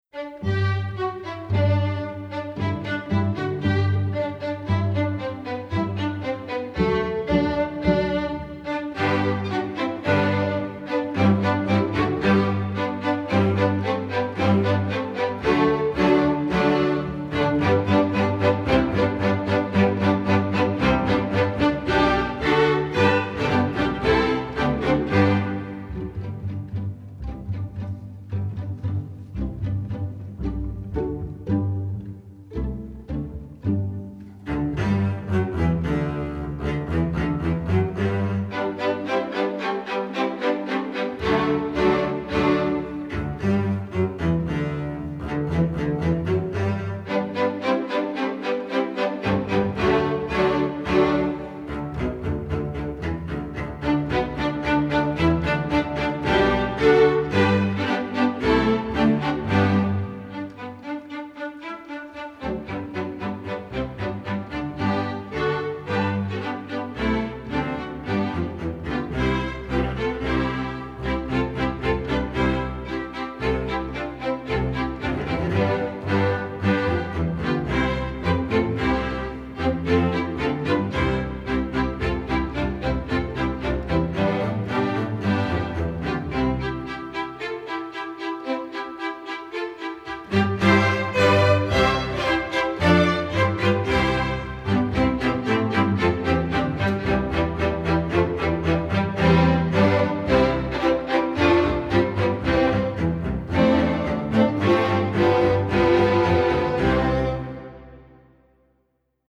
Composer: German Student Song
Voicing: String Orchestra